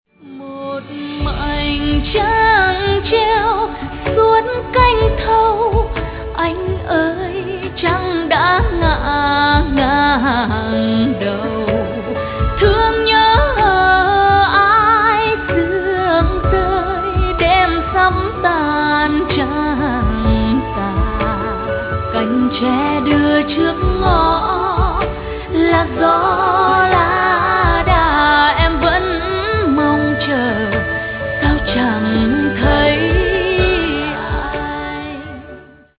Sáng tác: Dân Ca Bắc Bộ